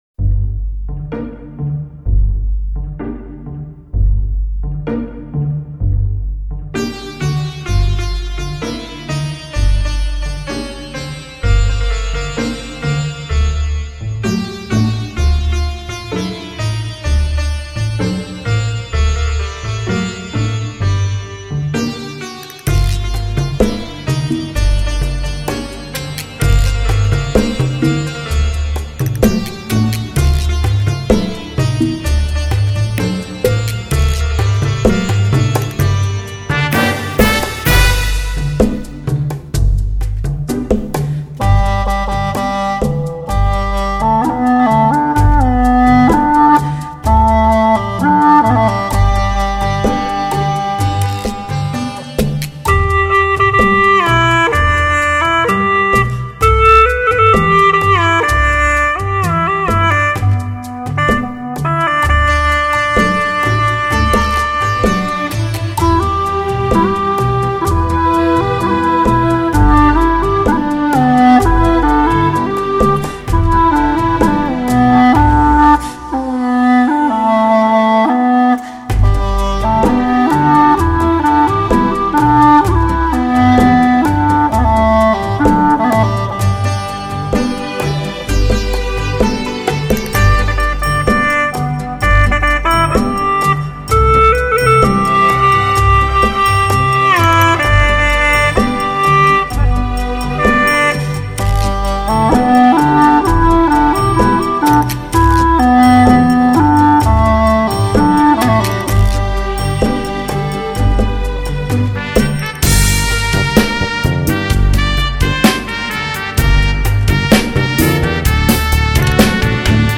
优美葫芦丝
活泼而又含蓄深情